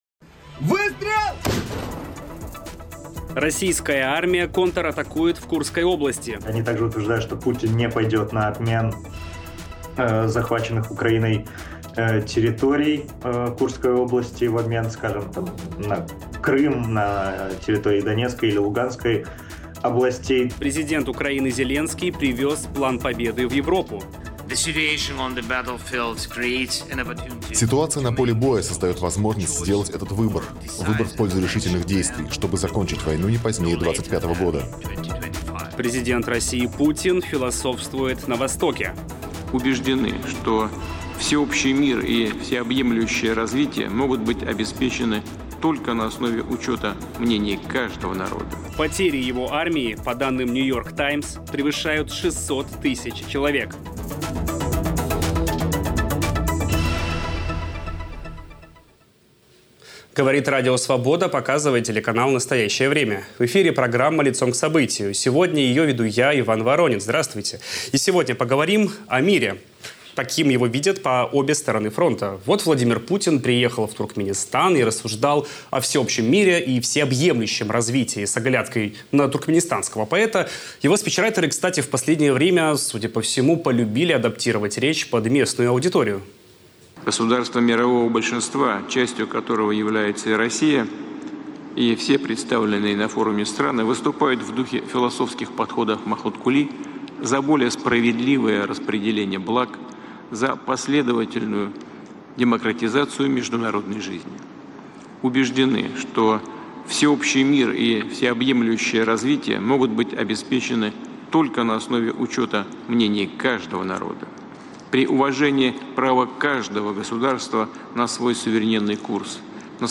Об этом говорим с военным аналитиком и социологом